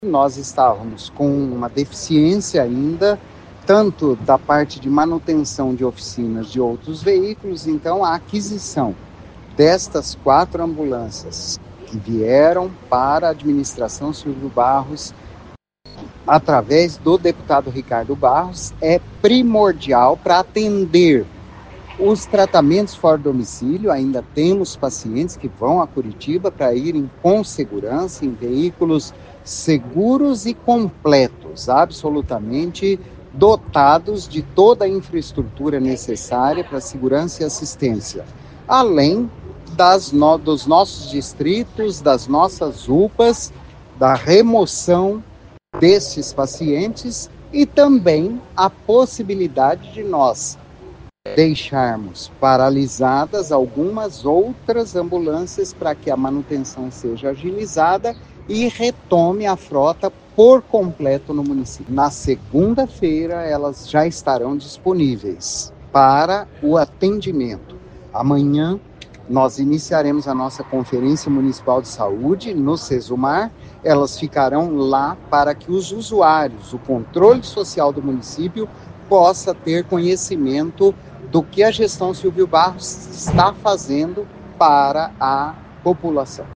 A Secretaria de Saúde de Maringá recebeu nesta quinta-feira (24) quatro ambulâncias para reforçar a frota de veículos utilizados no transporte de pacientes entre as unidades de saúde e em atendimentos fora do domicílio. O secretário de Saúde, Antônio Carlos Nardi, explica que havia uma deficiência na frota de ambulâncias.